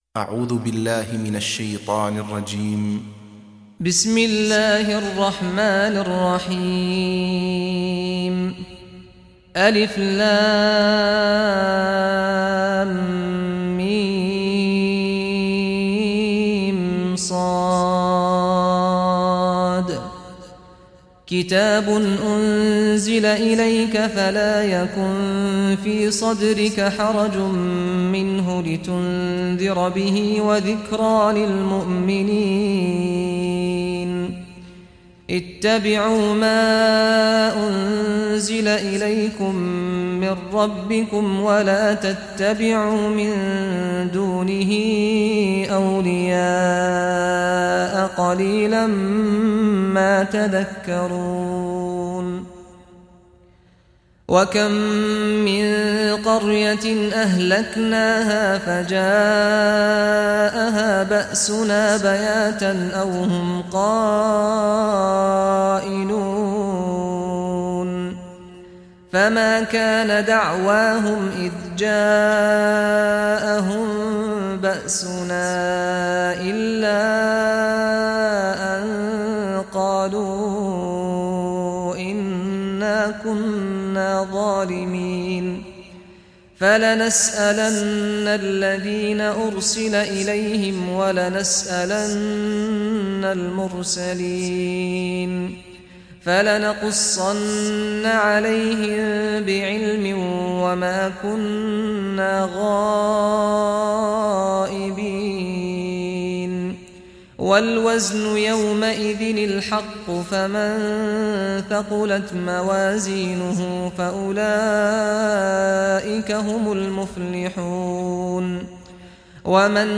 Surah Al-Araf Recitation by Sheikh Saad Al Ghamdi
Surah Al-Araf, listen or play online mp3 tilawat / recitation in Arabic in the beautiful voice of Imam Sheikh Saad al Ghamdi.